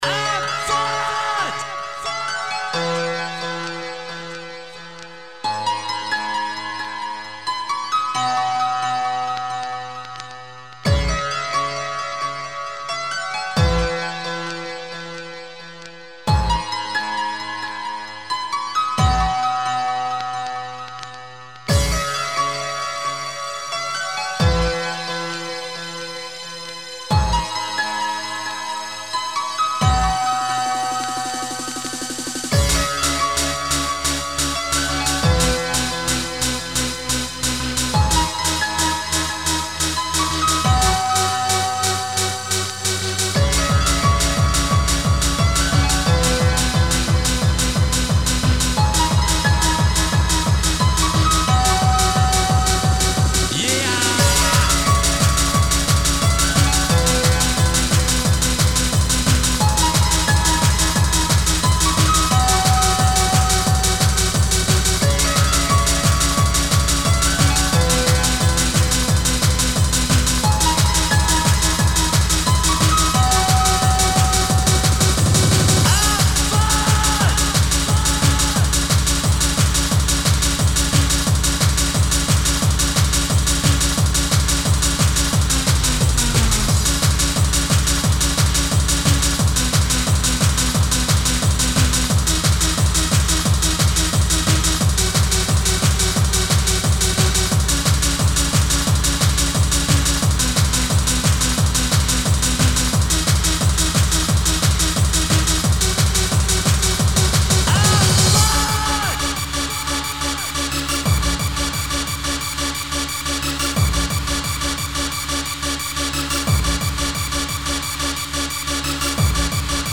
impulsive hard remix
• Quality: 22kHz, Mono